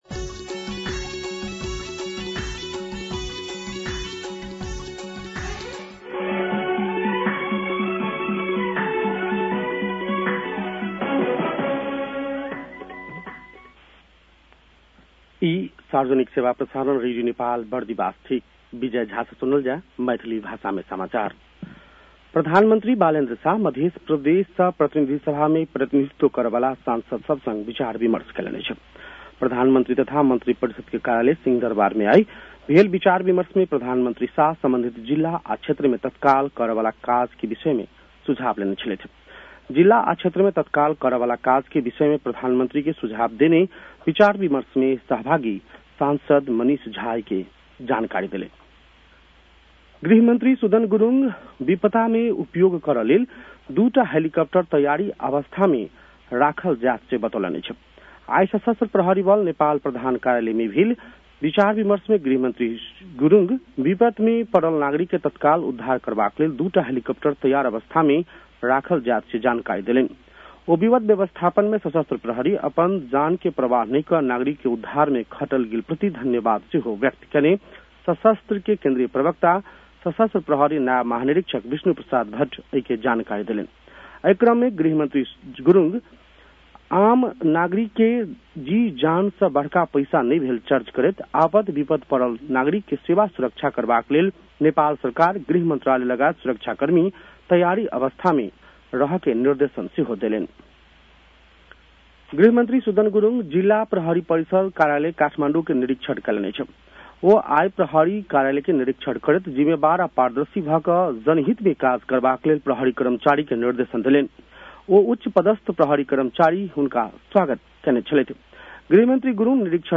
मैथिली भाषामा समाचार : १८ चैत , २०८२